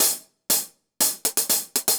Index of /musicradar/ultimate-hihat-samples/120bpm
UHH_AcoustiHatC_120-02.wav